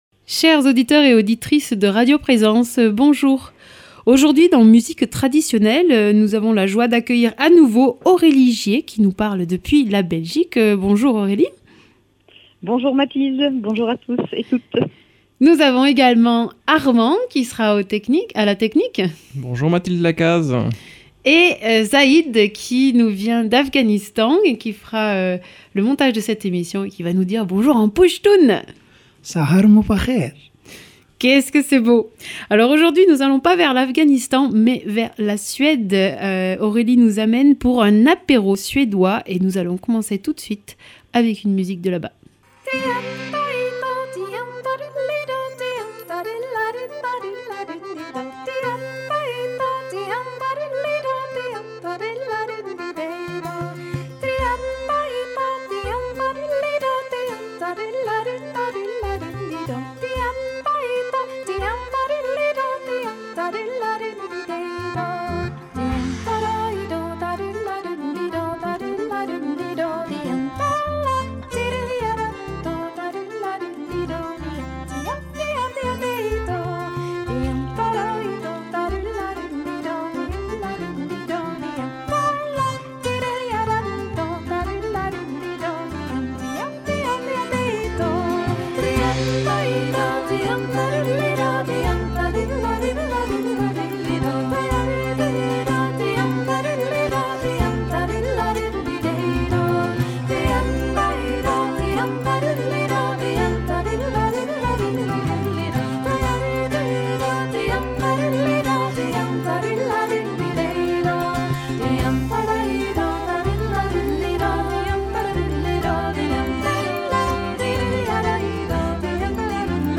Polska, schottis et autres délices suédois sont au programme, dans une diversité de formules musicales.